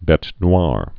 (bĕt nwär)